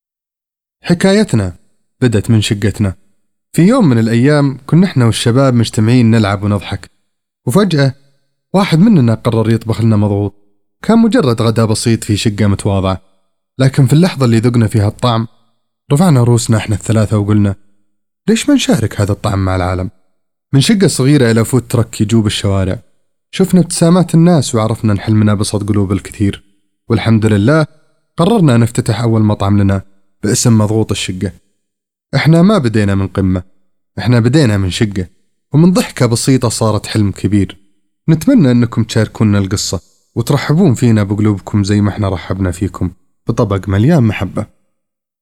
مطعم الشقة
مطعم-الشقه.wav